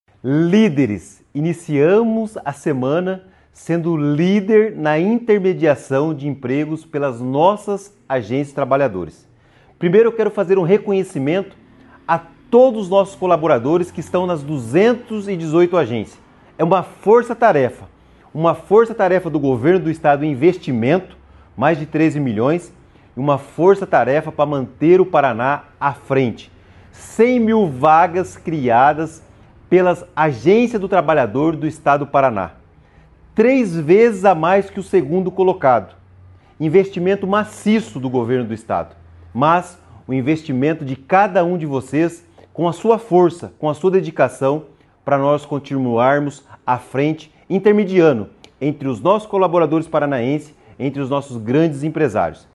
Sonora do secretário do Trabalho, Qualificação e Renda, Do Carmo, sobre a liderança do Paraná no 1º semestre nas contratações via Rede Sine